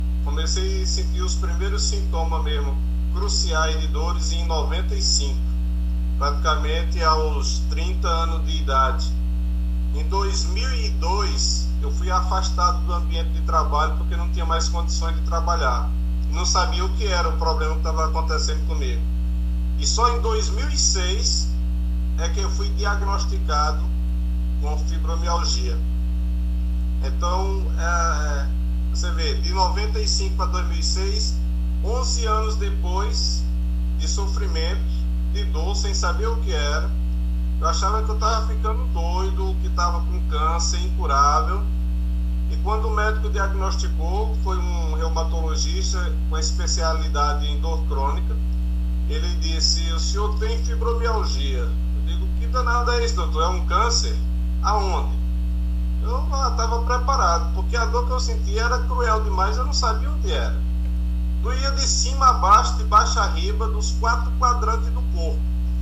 Depoimento completo